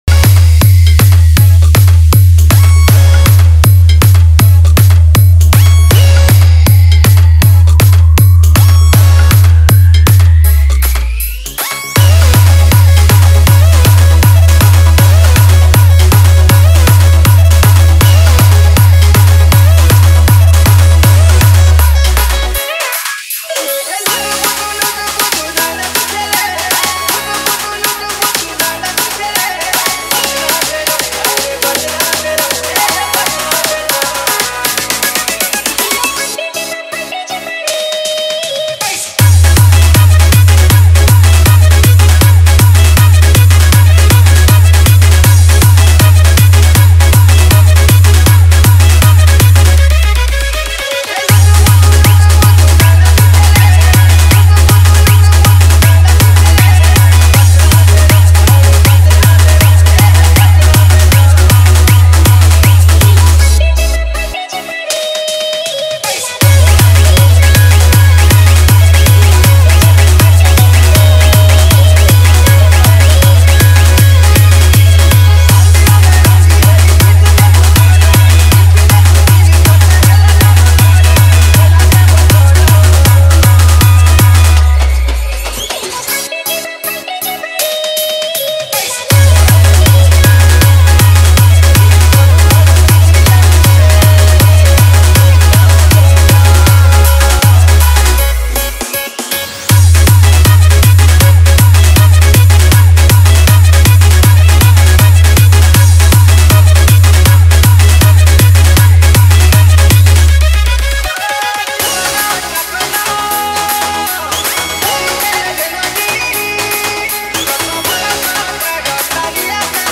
Humming Dance Remix